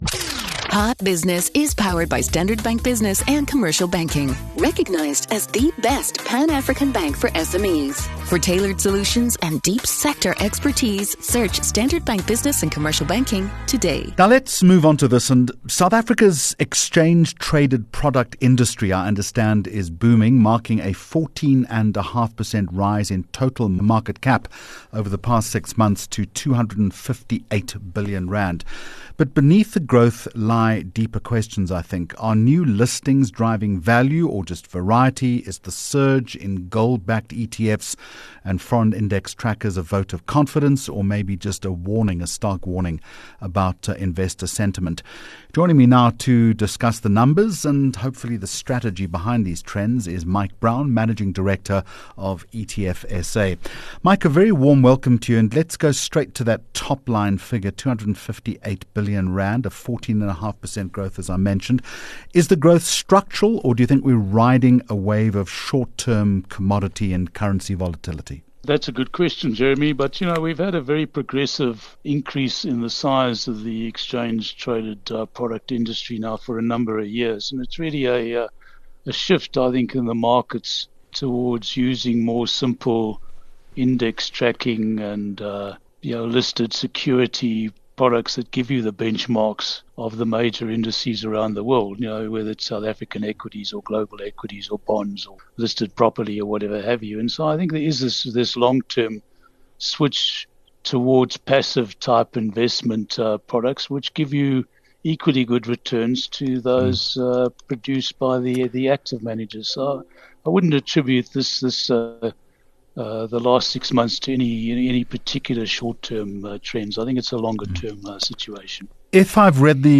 15 Jul Hot Business Interview